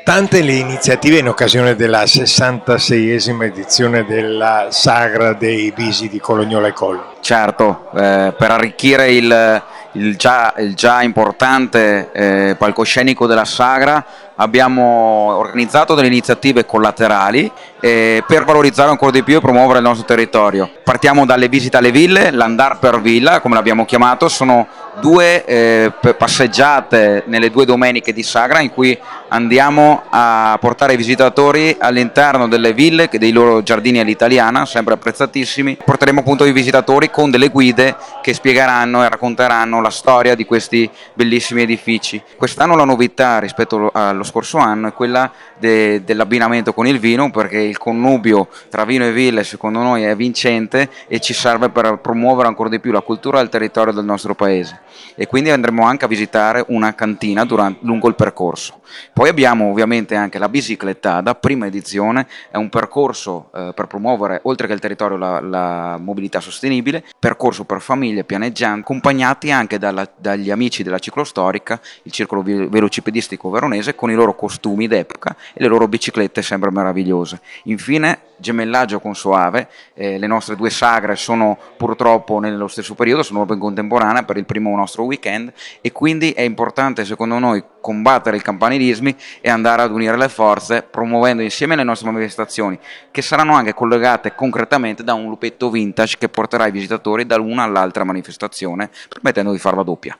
Enrico Martelletto, assessore alla valorizzazione del territorio di Colognola ai Colli